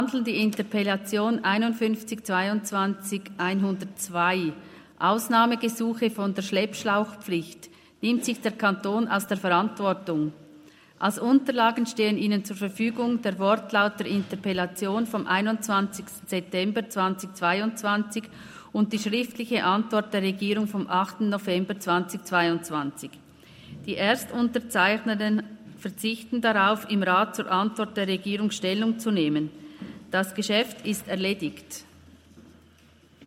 27.11.2023Wortmeldung
Session des Kantonsrates vom 27. bis 29. November 2023, Wintersession